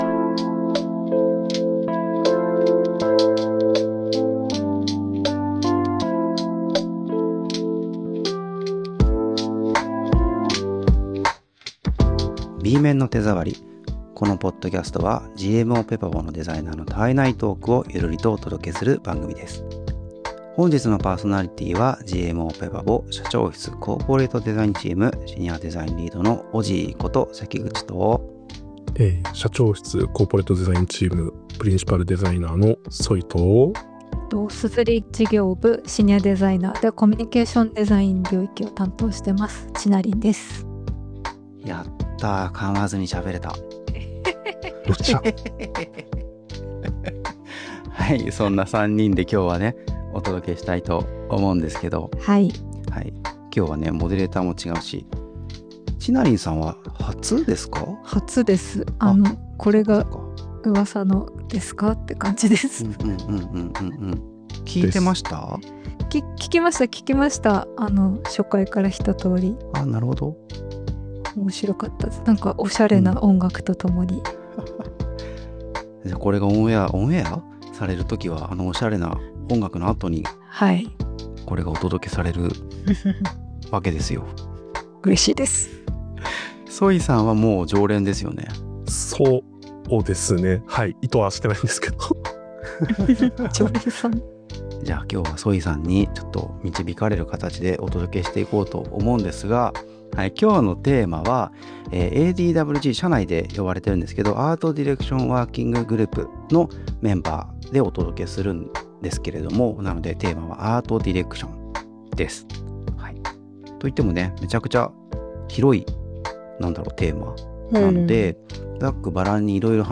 ペパボのアートディレクター3人で雑談しました。ADとは何か、若手の育成、自分たちのキャリア、身に染み付いた感覚…。悩みと展望・不安と期待について楽しく話しました。…